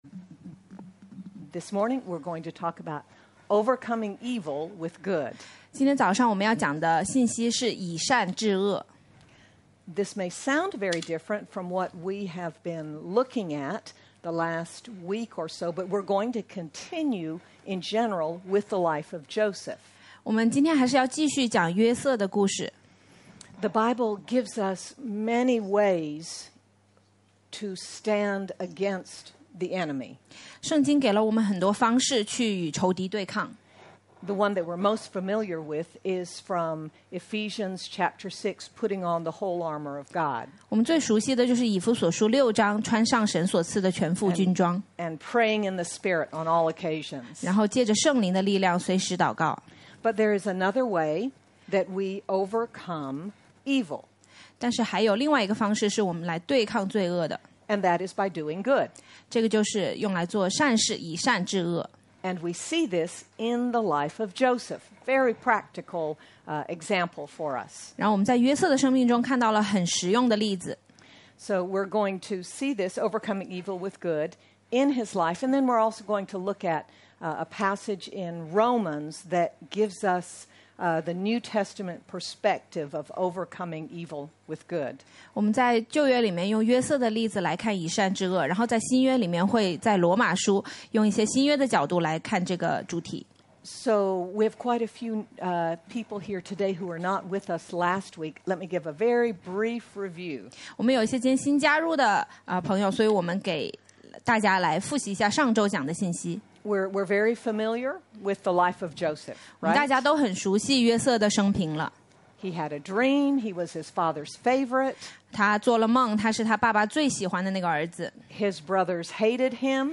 Mar 01, 2026 Overcome Evil with Good MP3 SUBSCRIBE on iTunes(Podcast) Notes Discussion In the concluding message on Joseph, who with God’s help, forgot the pain of the past and became fruitful in his time of trouble, we also learn how to overcome the wrong things done to us by instead doing good. Sermon by